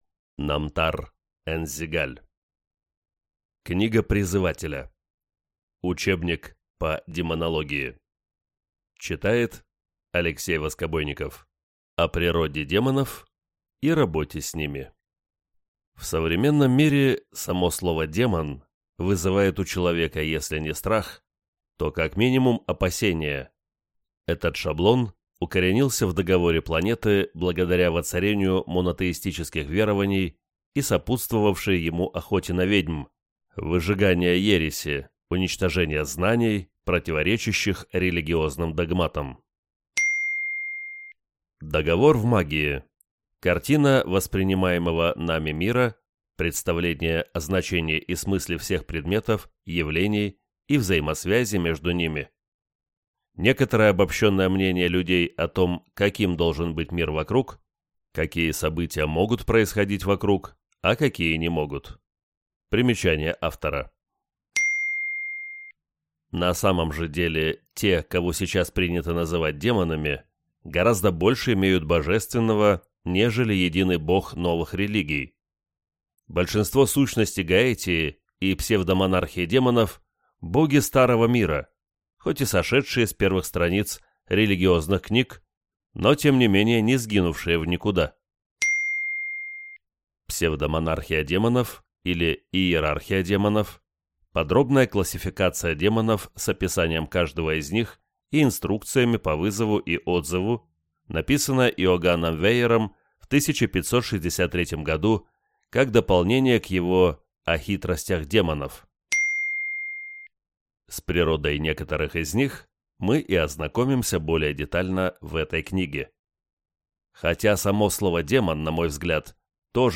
Аудиокнига Книга Призывателя. Учебник по демонологии | Библиотека аудиокниг